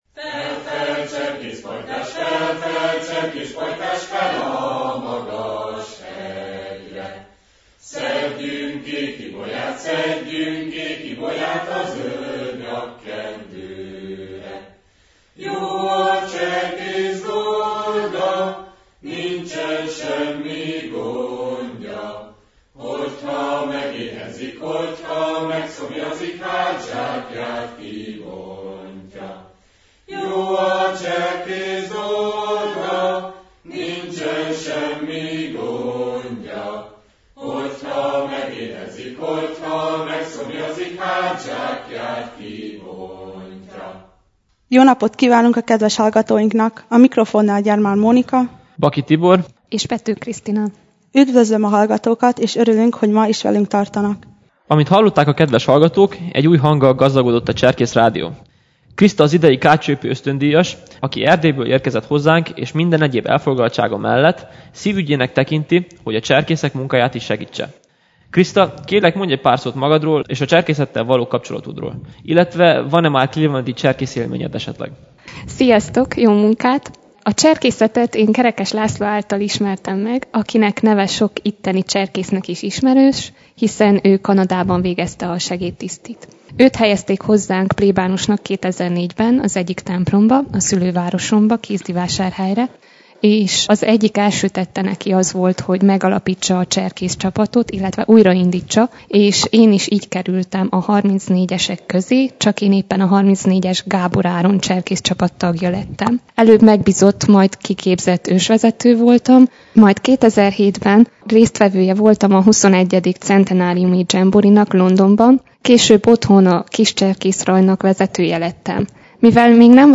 A hanganyag meghallgatható a vasárnapi élőadás után itt a weboldalon is.